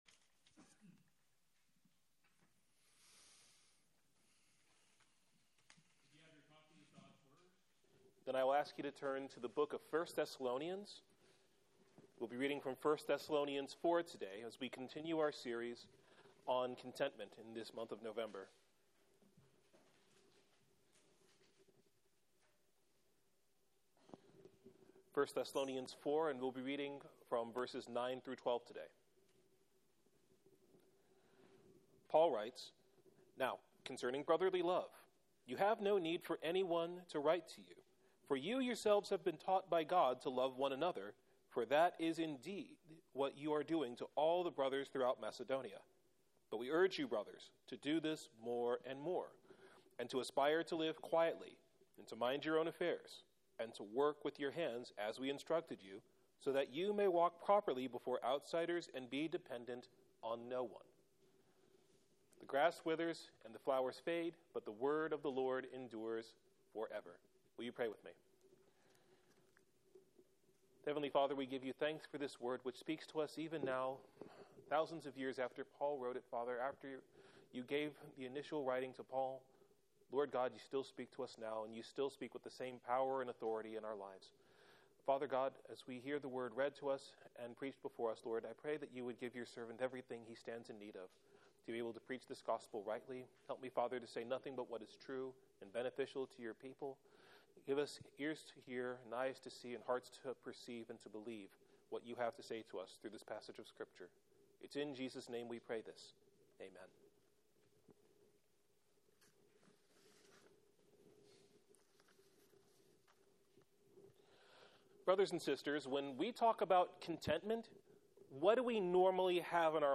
Sermon Text: 1 Thessalonians 4:9-12